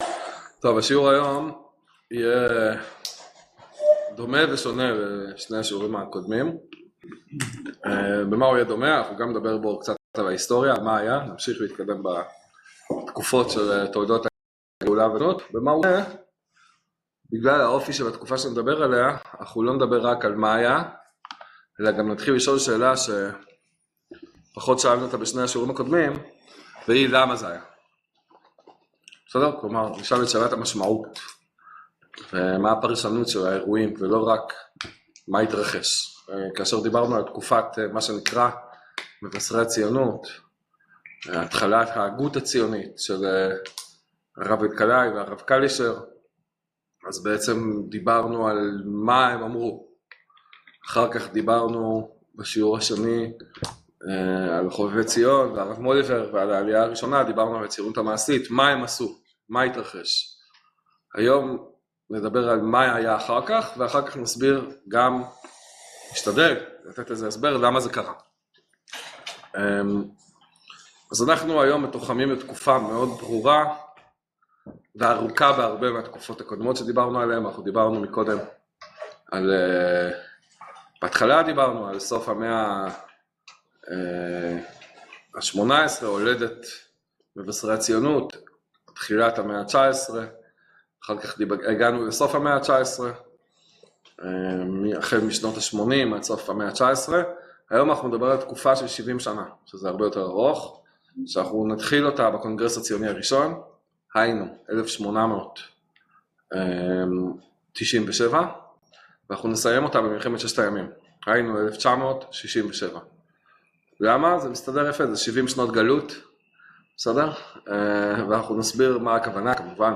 שיעור מרתק המסכם את תולדות את הציונות ואת המשמעות שלה לימינו